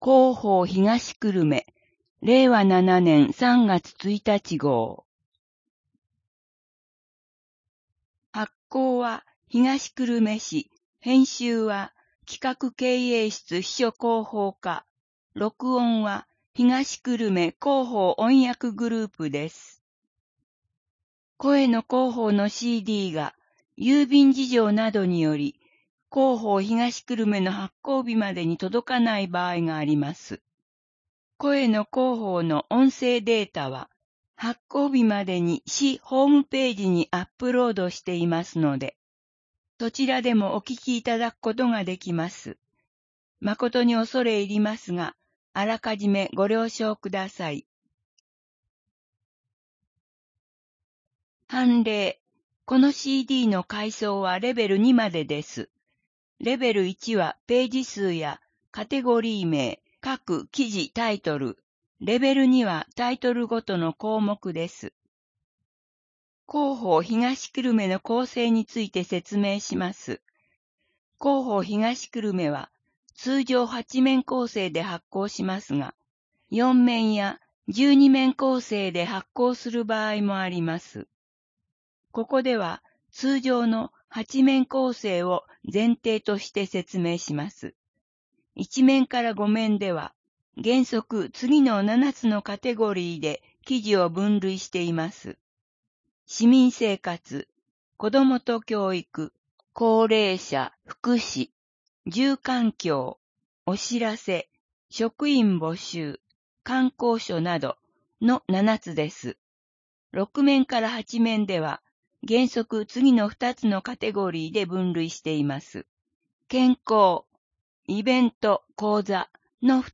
声の広報（令和7年3月1日号）